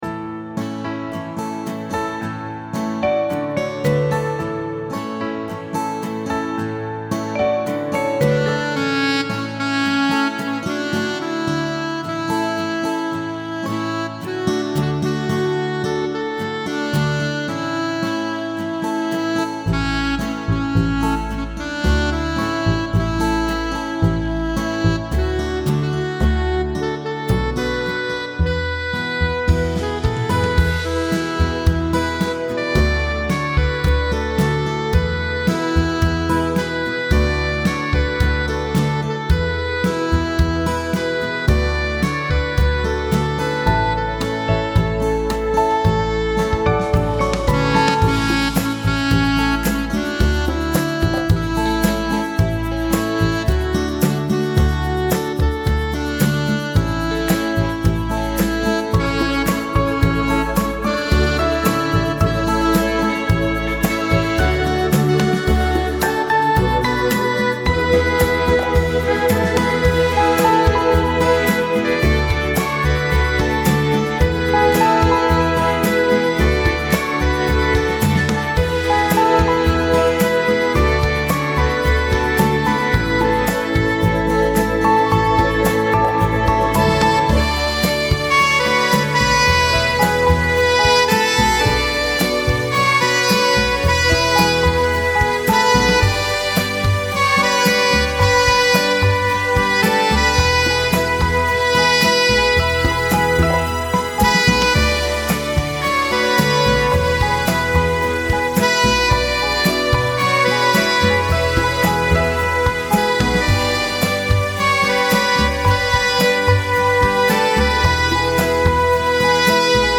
lyrics. mp3 backing w. melody